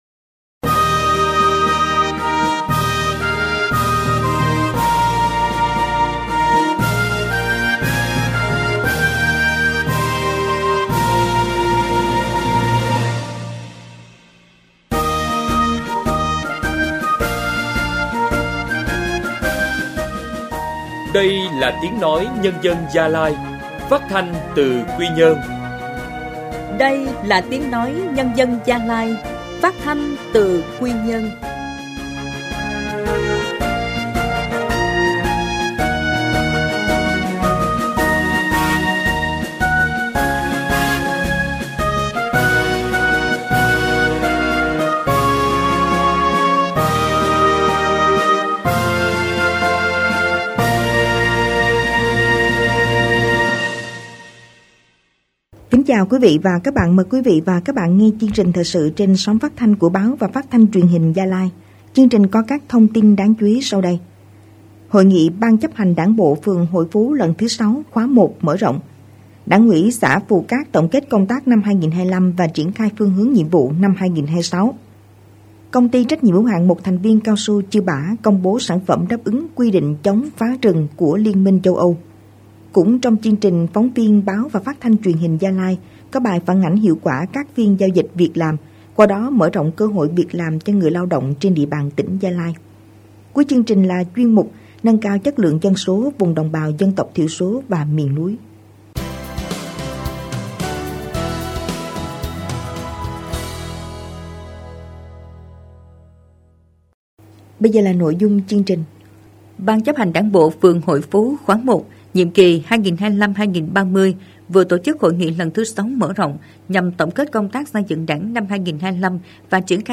Thời sự phát thanh trưa